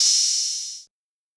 Metro OP Hat 1.wav